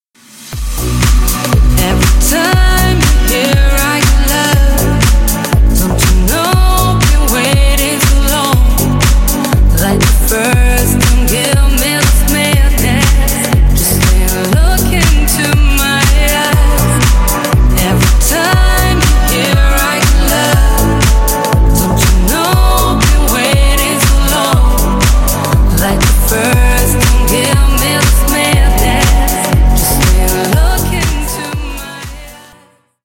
Ремикс # Танцевальные
клубные